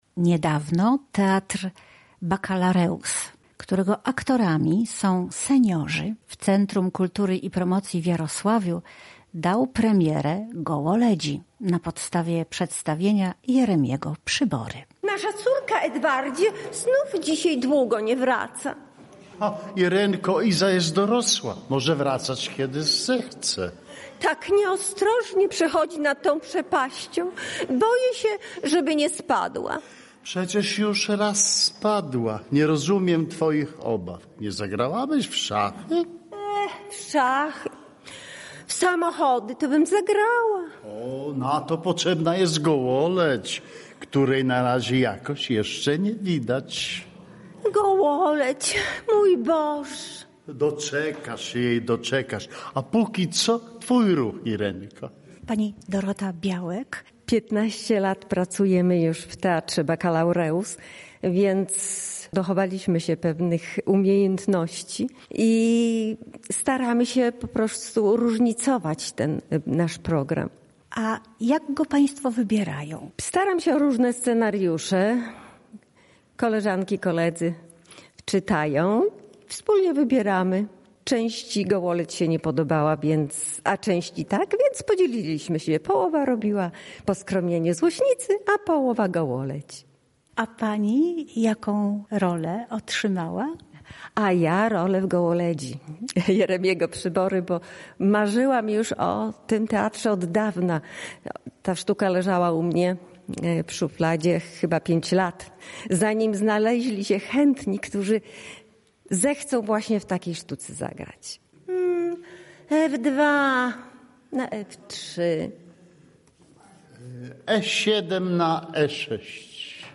Podczas próby